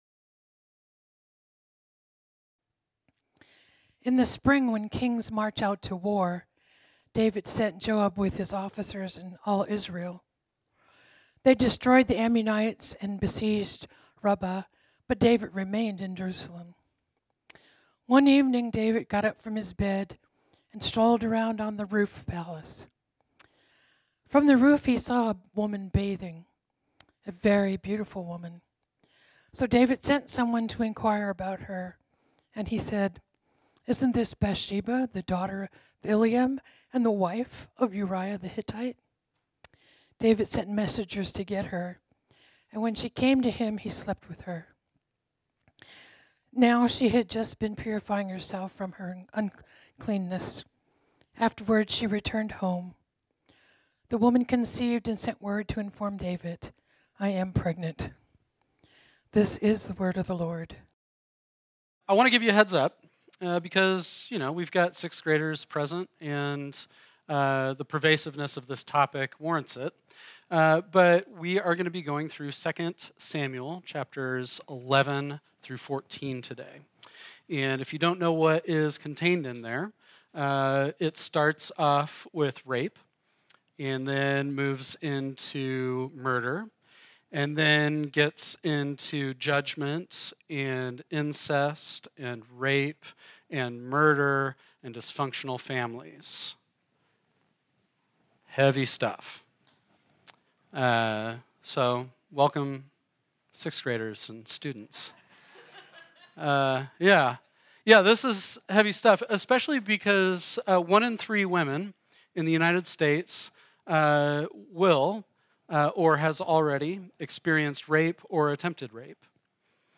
This sermon was originally preached on Sunday, July 30, 2023.